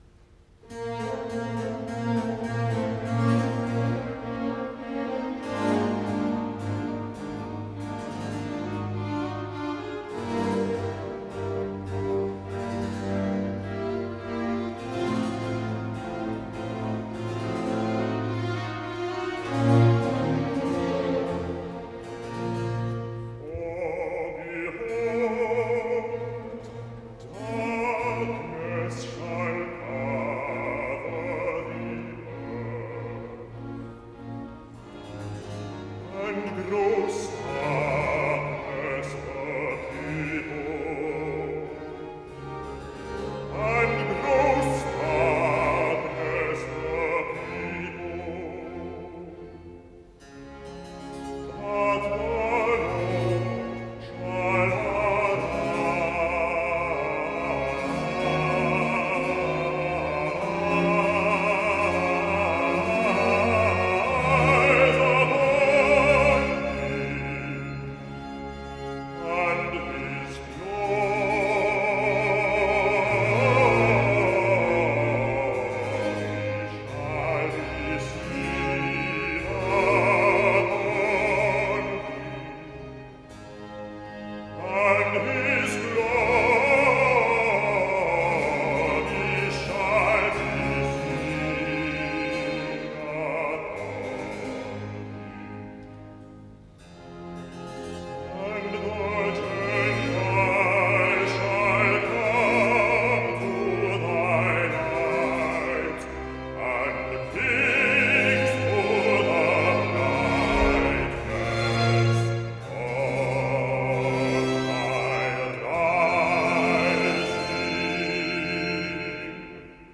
Recitative-bass